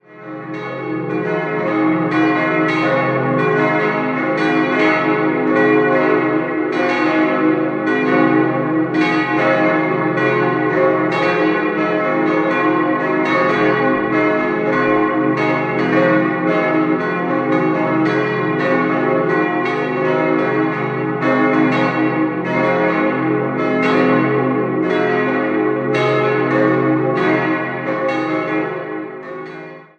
Idealquartett c'-es'-f'-as' D ie kleine Glocke stammt noch aus der Vorkriegszeit und wurde 1910 von den Gebrüdern Oberascher in München gegossen. Die drei anderen wurden 1953 von Petit&Edelbrock in Gescher hergestellt.